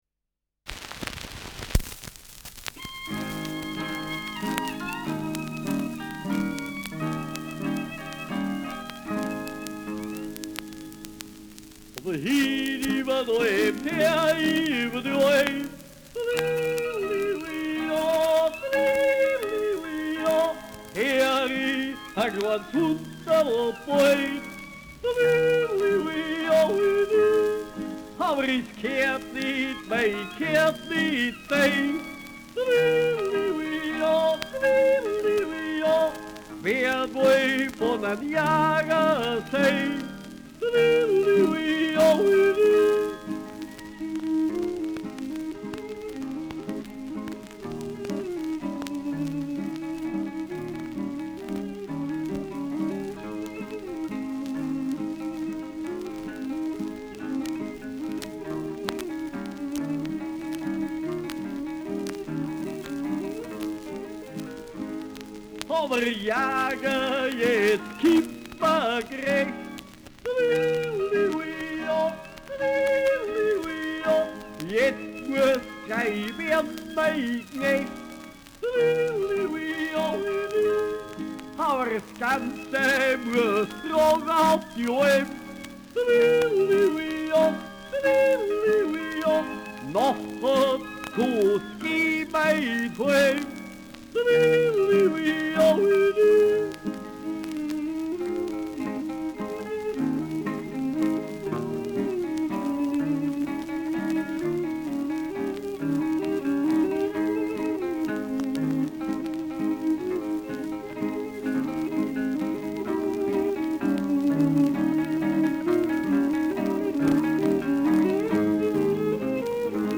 Schellackplatte
Durchgehend leichtes Knistern : Vereinzelt stärkeres Knacken : Leichtes Klirren an lauteren Stellen
Tegernseer Trio (Interpretation)
[München] (Aufnahmeort)
Stubenmusik* FVS-00016